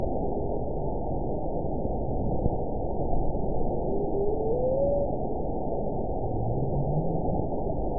event 921980 date 12/24/24 time 04:24:58 GMT (11 months, 1 week ago) score 8.80 location TSS-AB02 detected by nrw target species NRW annotations +NRW Spectrogram: Frequency (kHz) vs. Time (s) audio not available .wav